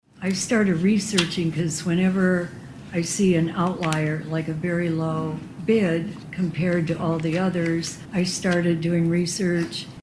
Ward 4 Councilwoman Carolyn Siemann says Top Saw’s bid coming in well below the other contractors caused her to look into the company further.